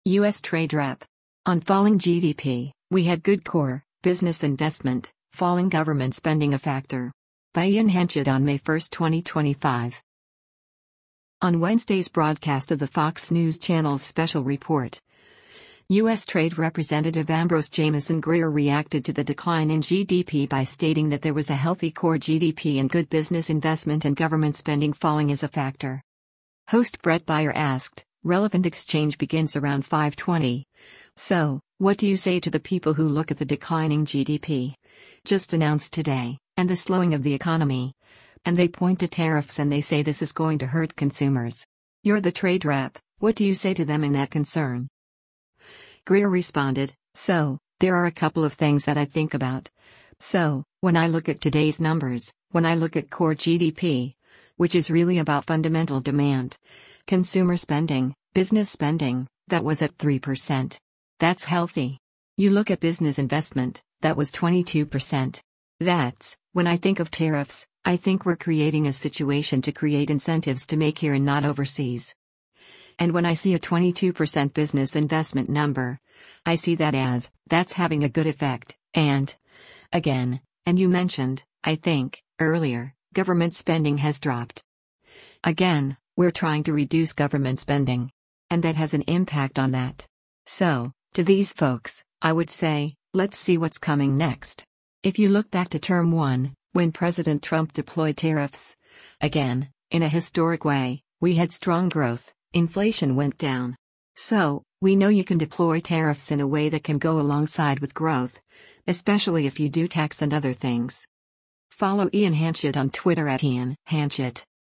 On Wednesday’s broadcast of the Fox News Channel’s “Special Report,” U.S. Trade Representative Amb. Jamieson Greer reacted to the decline in GDP by stating that there was a “healthy” core GDP and good business investment and government spending falling is a factor.